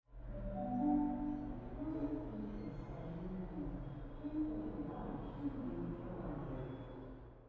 announcement3.ogg